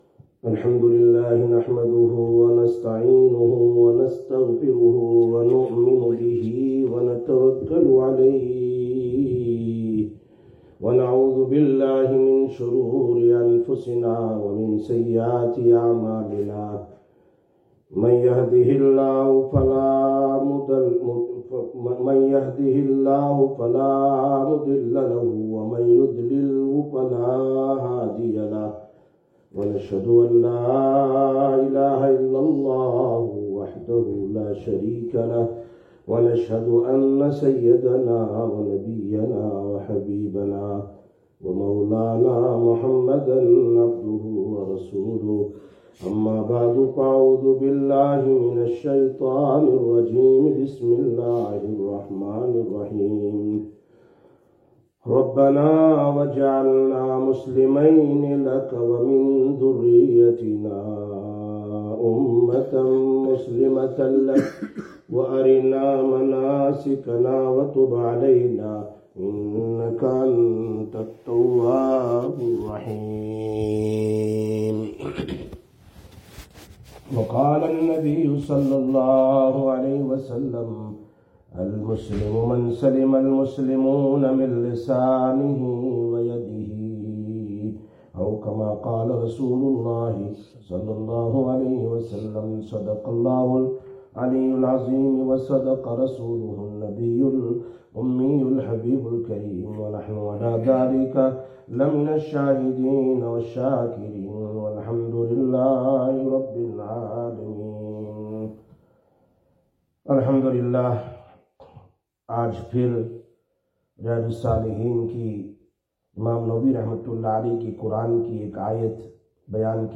25/12/2024 Sisters Bayan, Masjid Quba